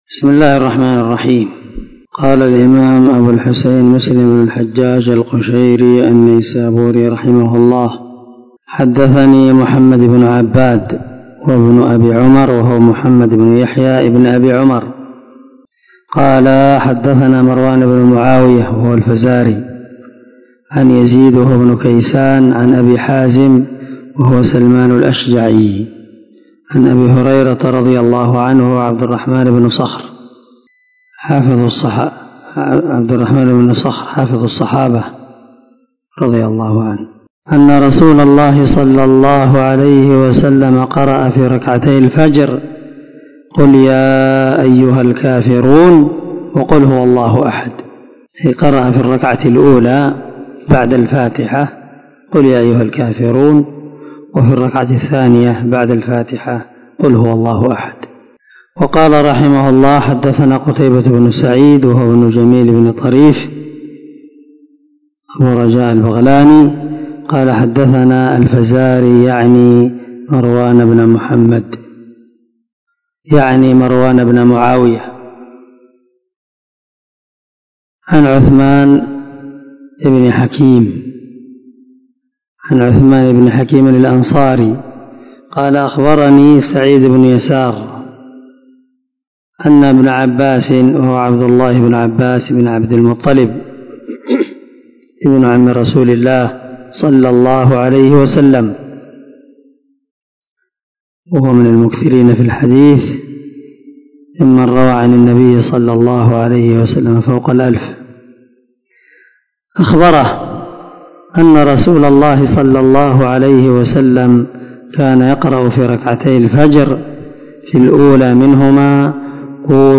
450الدرس 18 من شرح كتاب صلاة المسافر وقصرها حديث رقم ( 726 – 727 ) من صحيح مسلم